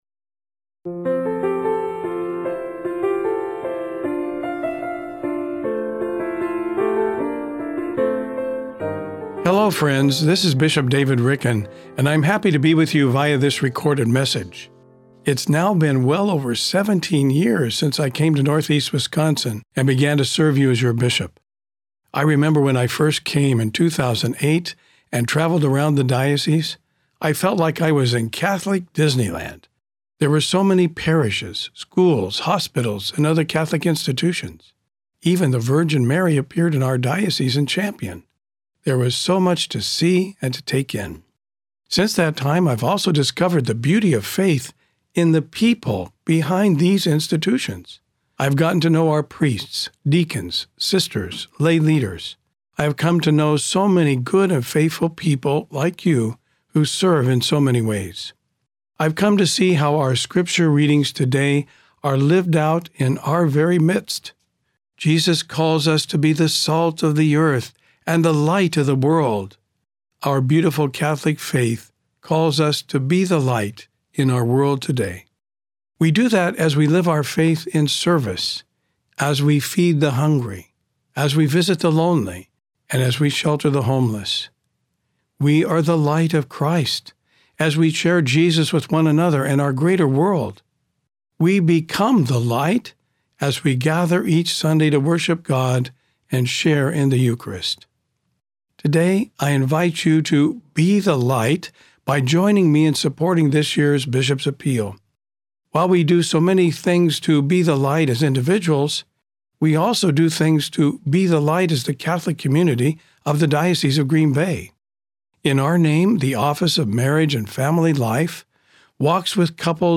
Bishop Ricken's Homily on the 2026 Bishop's Appeal